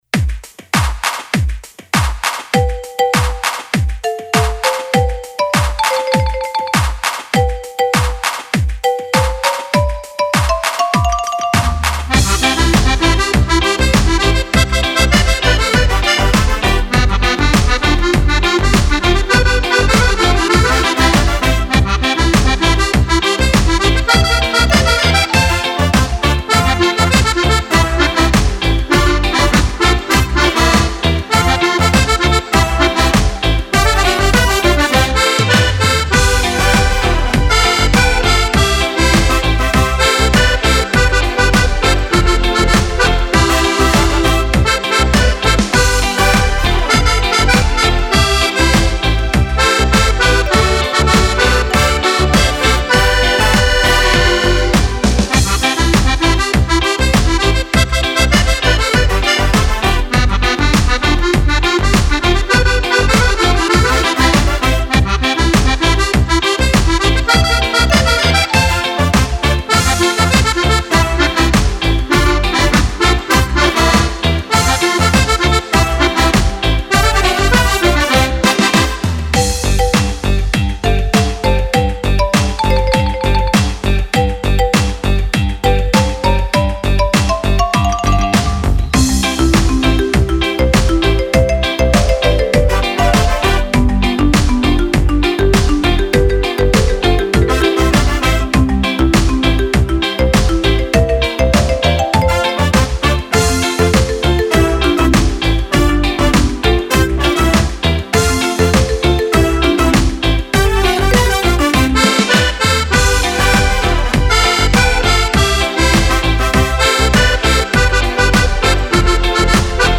Meneaito
Album di ballabili  per Fisarmonica.